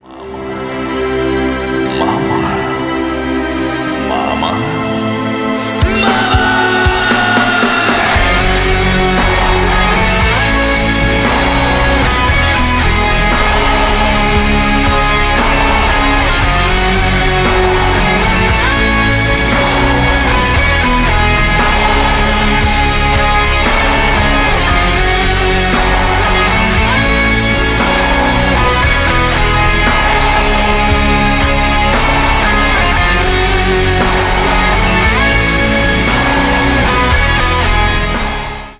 Рок Металл
громкие # кавер